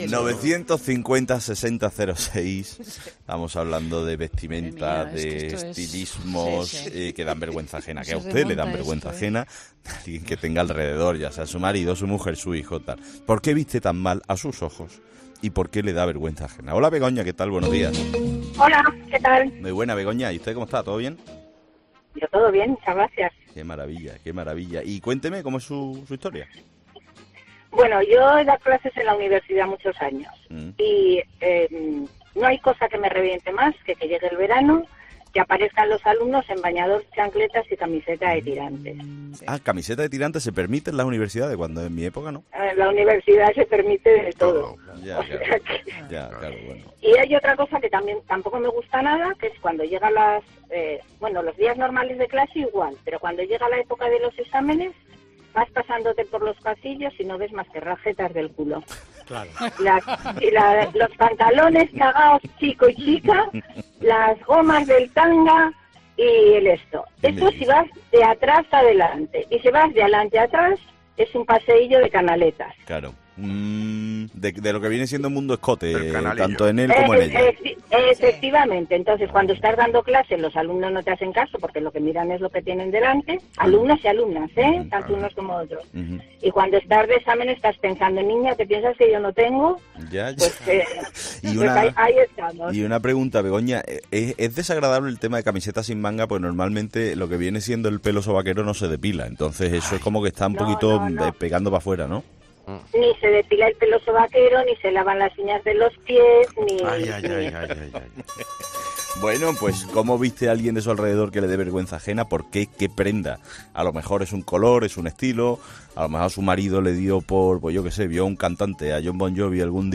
Como siempre...¡hablamos con nuestros 'fósforos'!